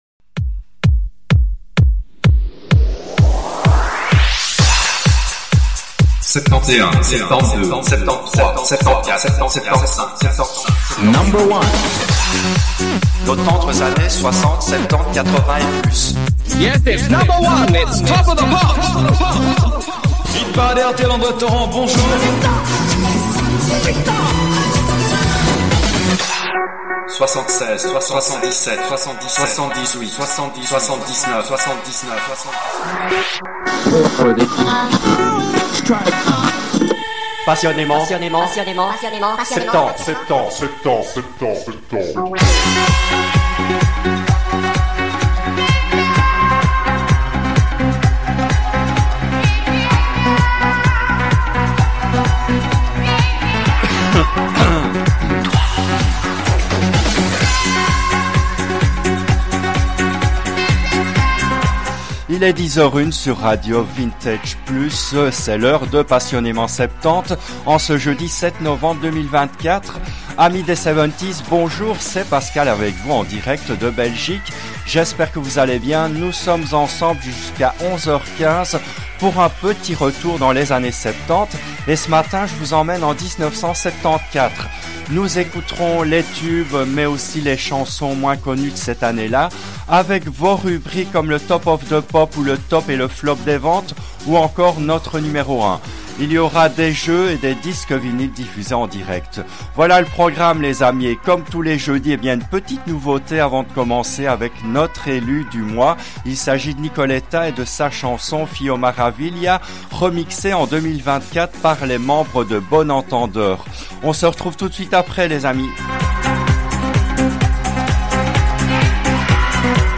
L’émission a été diffusée en direct le jeudi 07 novembre 2024 à 10h depuis les studios belges de RADIO VINTAGE PLUS.